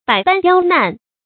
百般刁難的讀法